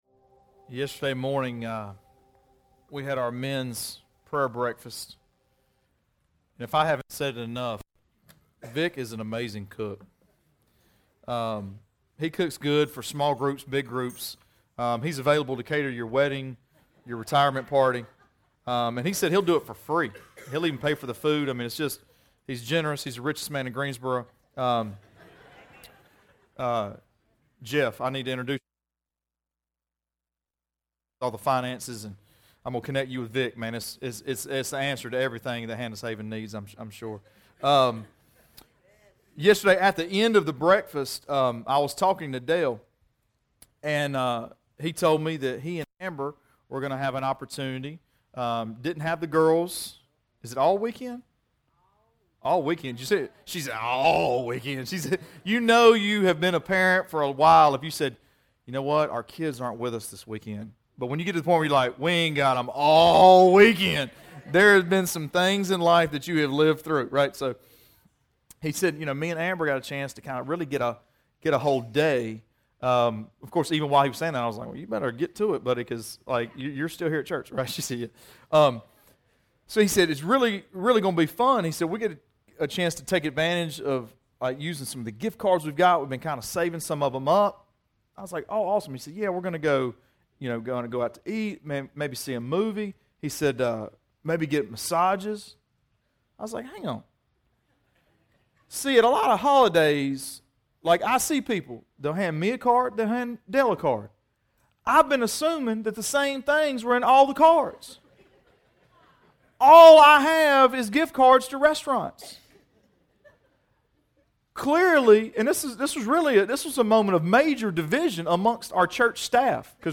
Sermons Archive - Page 28 of 60 - REEDY FORK COMMUNITY CHURCH